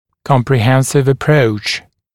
[ˌkɔmprɪ’hensɪv ə’prəuʧ][ˌкомпри’хэнсив э’проуч]комплексный подход